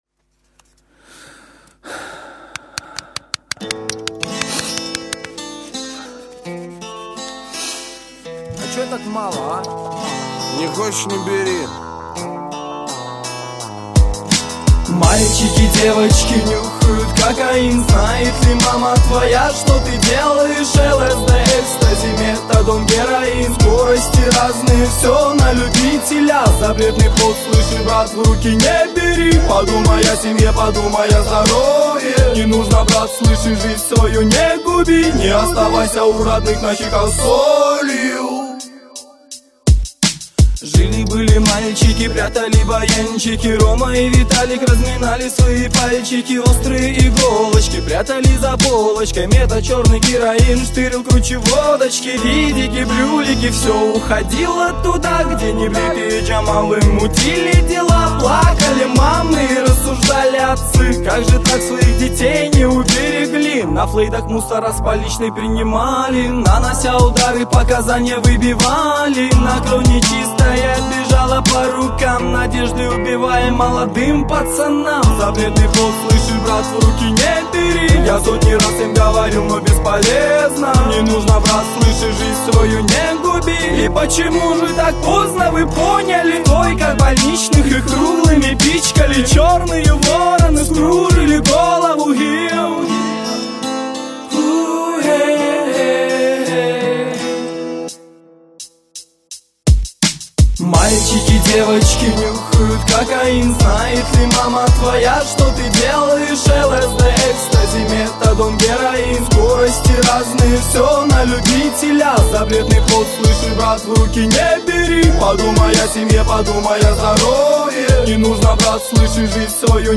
Категория: Hip-Hop - RAP